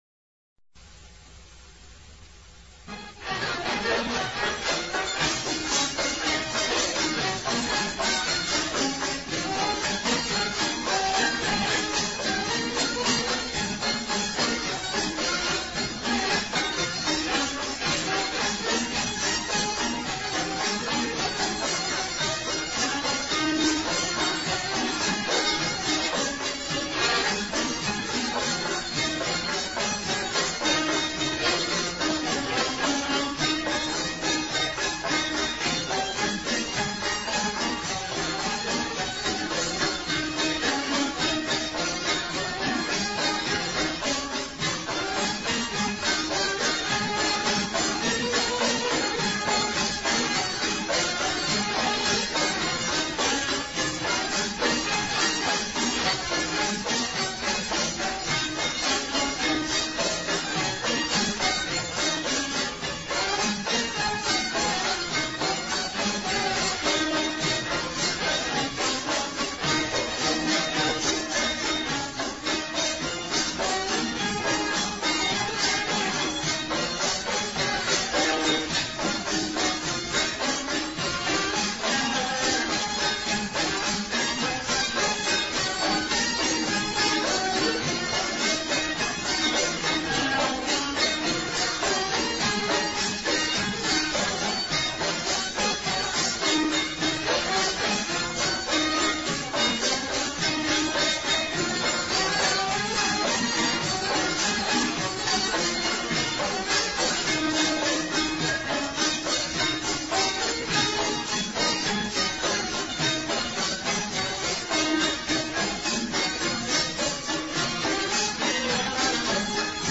Voici un enregistrement des années Radio Tlemcen (années 50) de la Touchia Raml_Al_Maya exécutée par l'Orchestre de Cheikh Larbi Ben Sari.
On remarquera que cette version est pratiquement identique à celle de redouane Bensari.